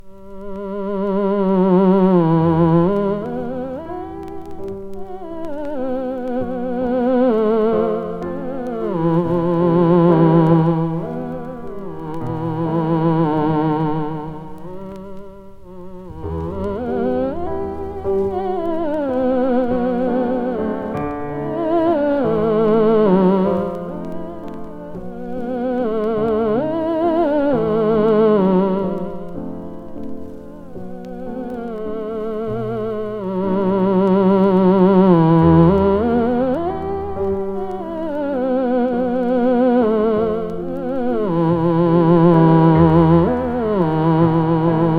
超絶な演奏に驚きます。